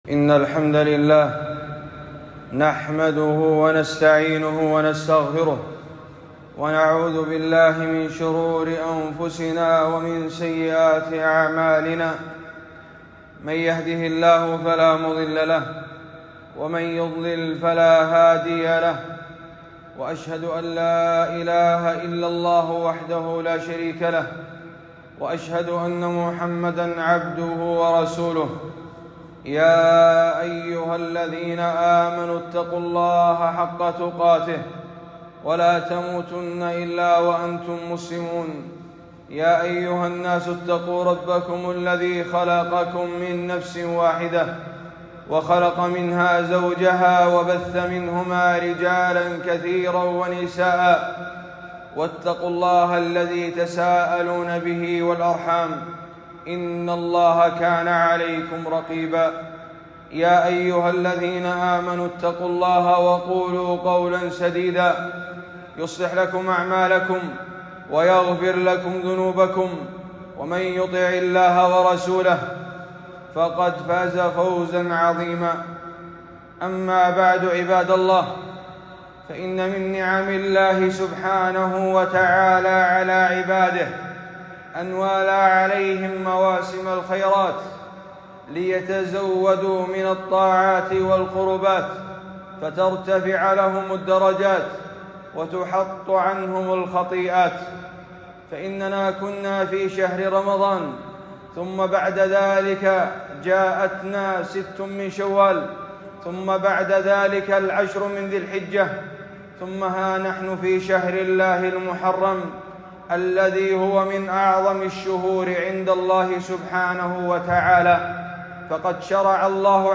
خطبة - أحكام عاشوراء وفضله وردٌّ على المبطلين